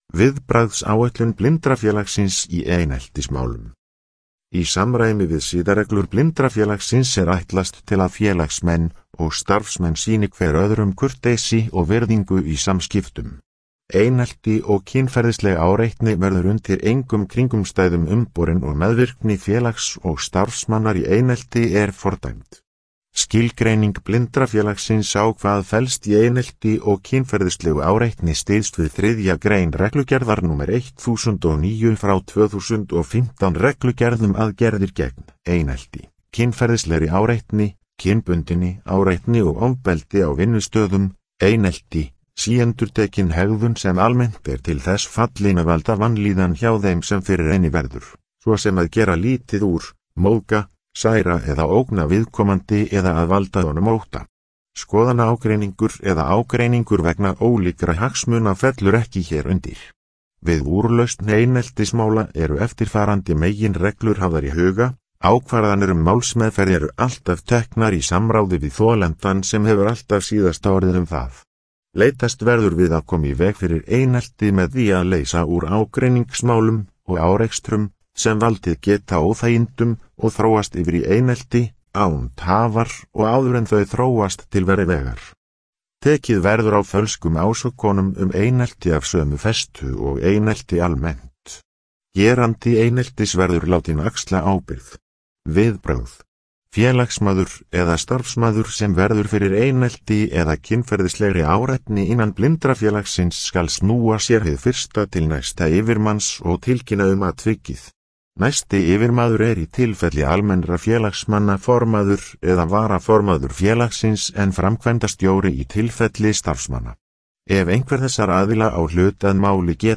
Upplesið